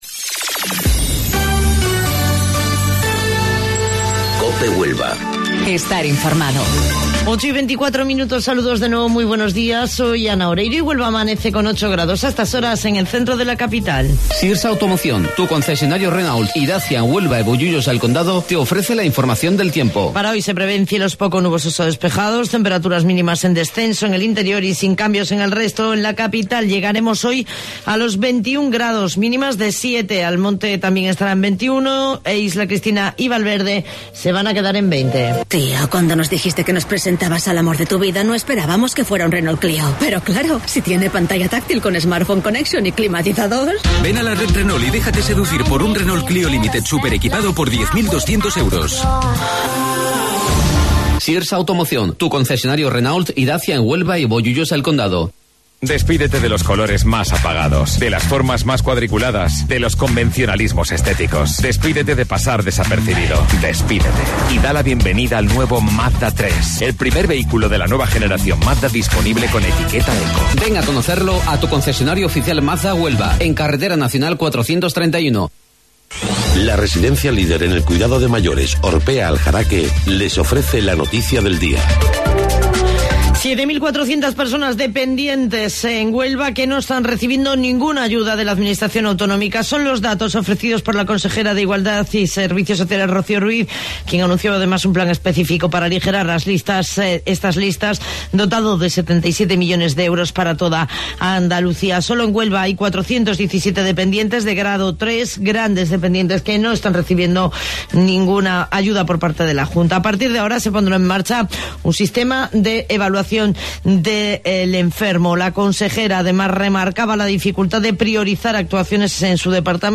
AUDIO: Informativo Local 08:25 del 21 de Marzo